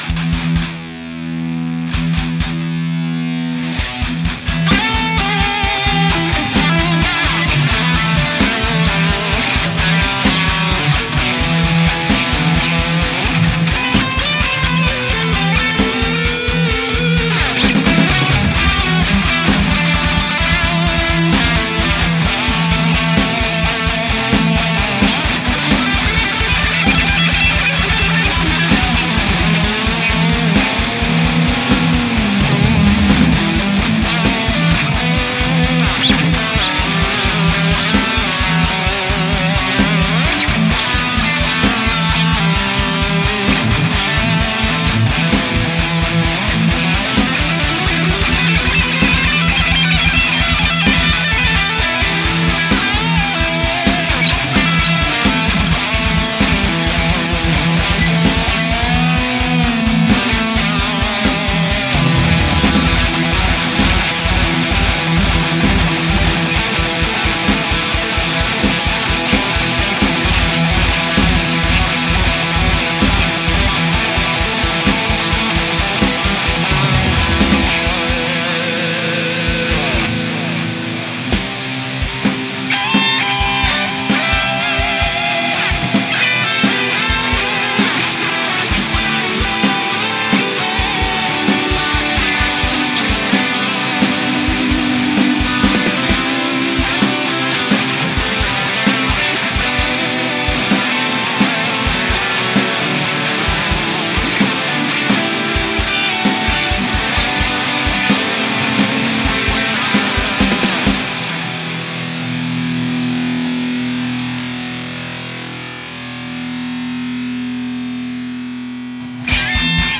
metal
Rock
Guitar